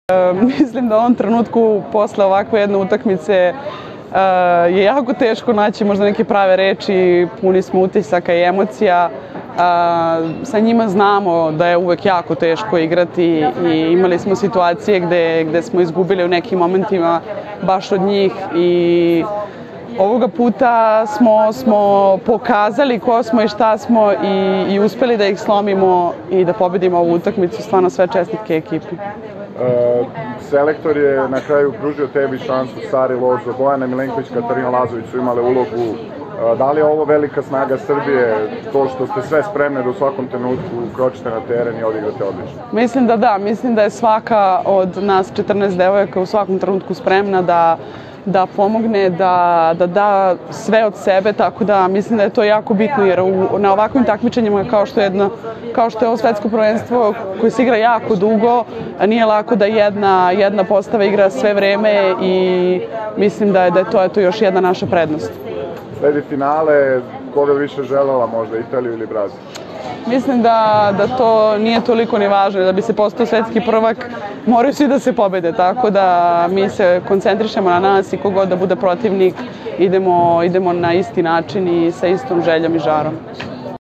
Izjava Mine Popović